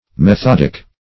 Methodic \Me*thod"ic\, Methodical \Me*thod"ic*al\, a. [L.